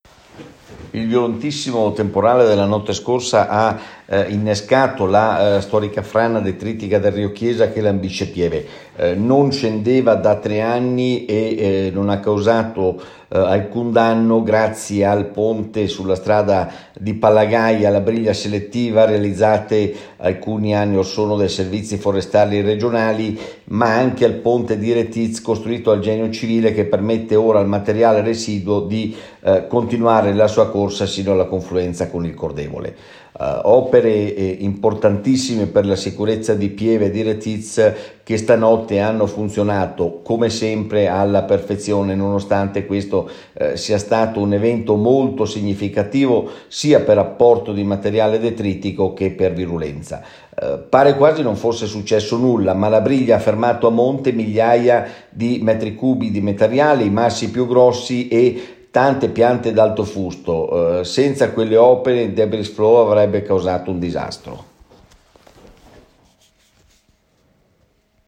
IL SINDACO LEANDRO GRONES